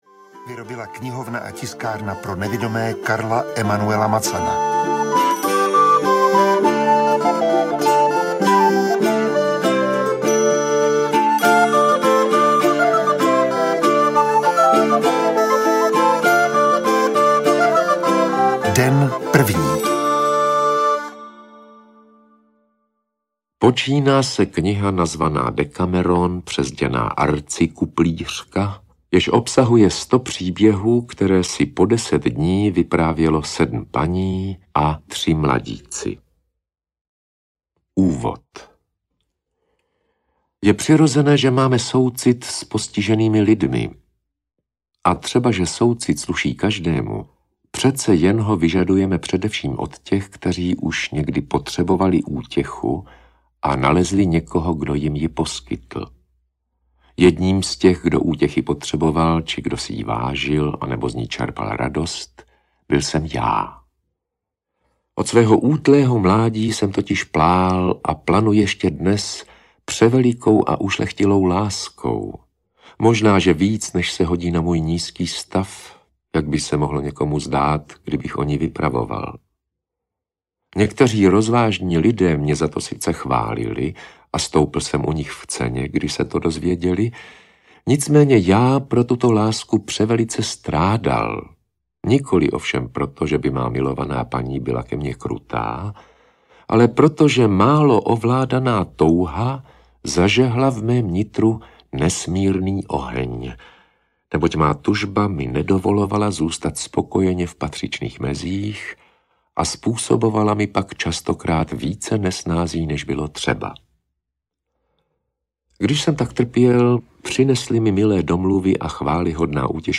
Dekameron (komplet) audiokniha
Ukázka z knihy
• InterpretRudolf Pellar